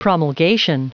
Prononciation du mot promulgation en anglais (fichier audio)
promulgation.wav